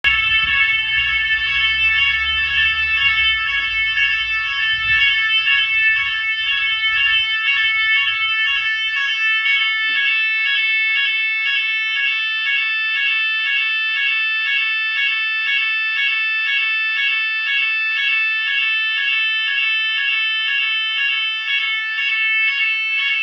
alarme_incendie